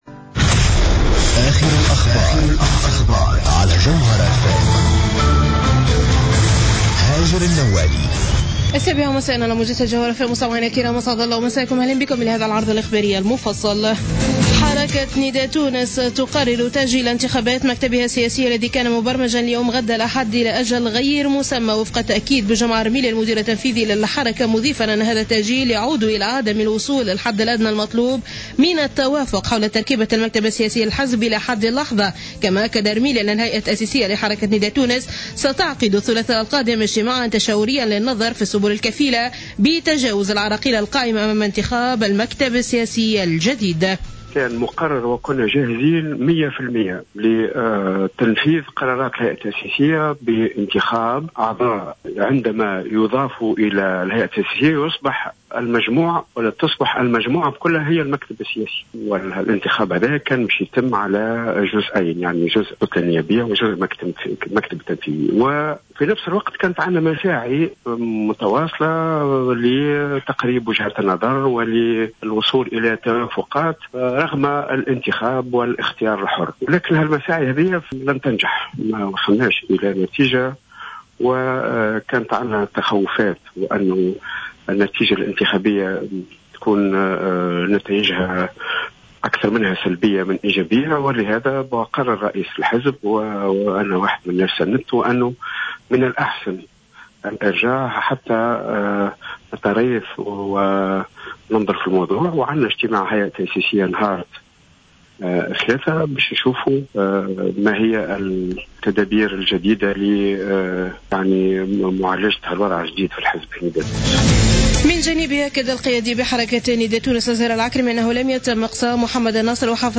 نشرة أخبار السابعة مساء ليوم الأحد 08 مارس 2015